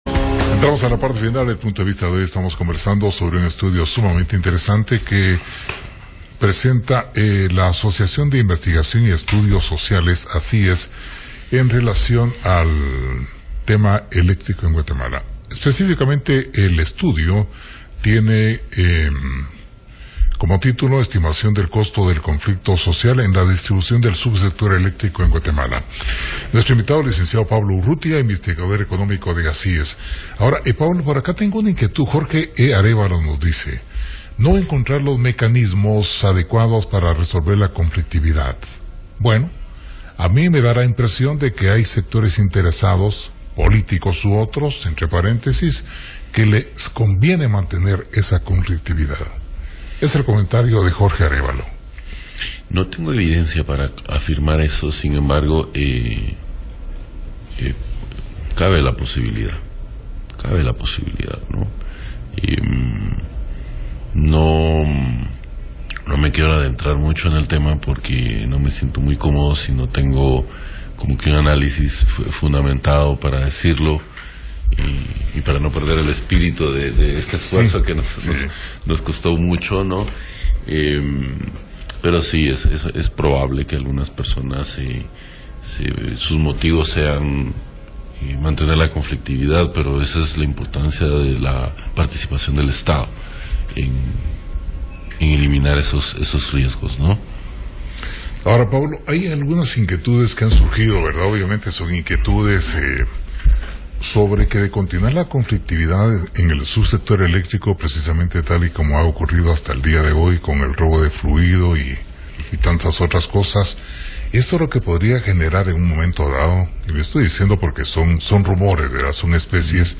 PUNTO DE VISTA / RADIO PUNTO: Entrevista con